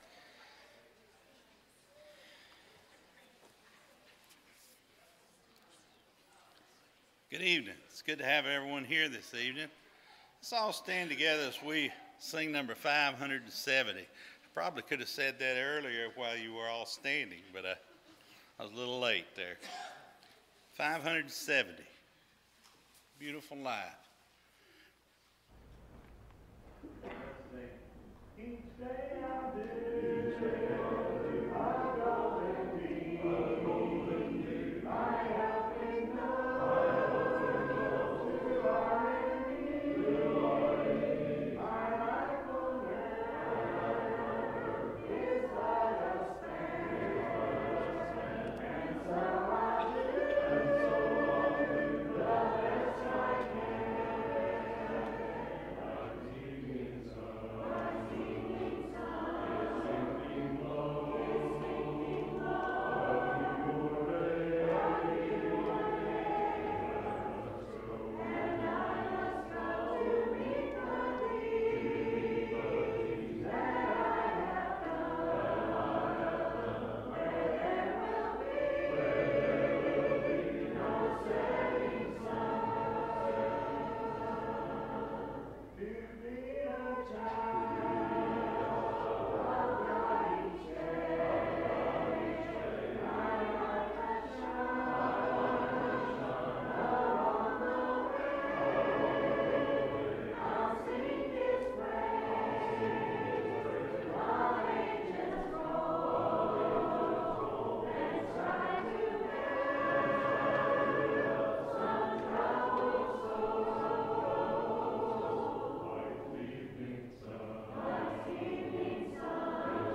Romans 12:21, English Standard Version Series: Sunday PM Service